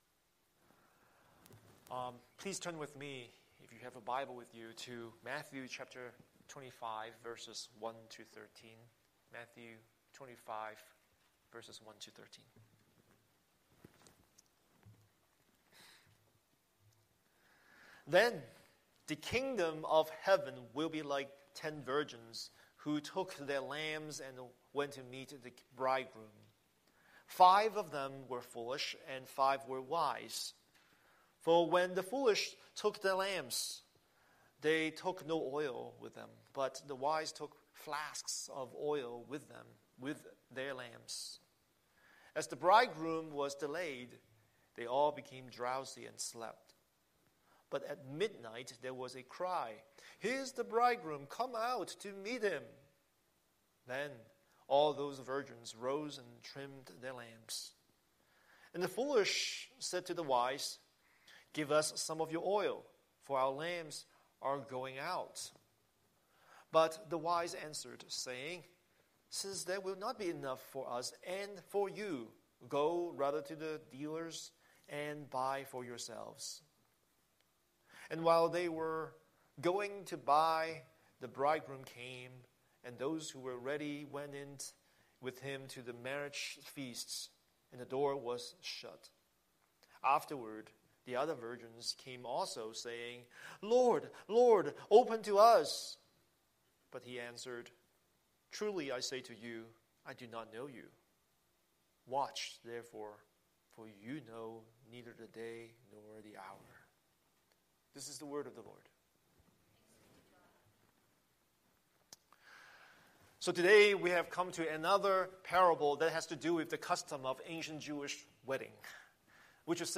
Scripture: Matthew 25:1–13 Series: Sunday Sermon